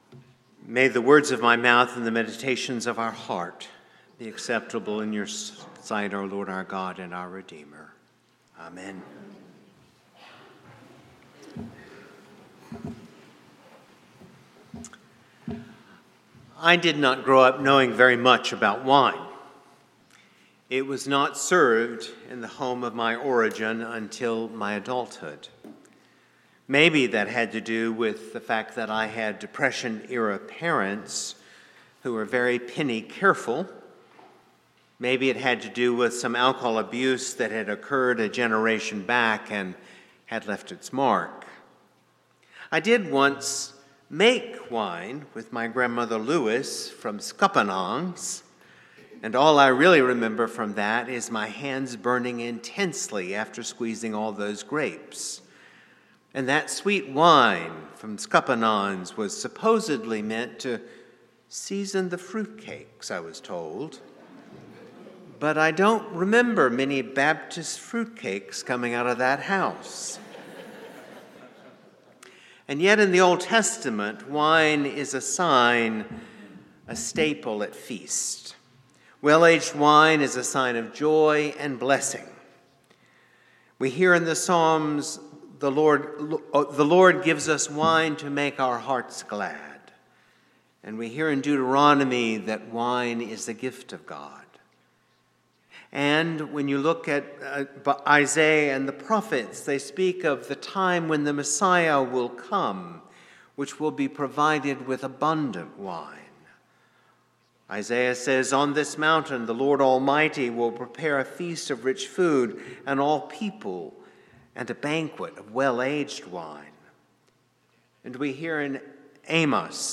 St-Pauls-HEII-9a-Homily-19JAN25.mp3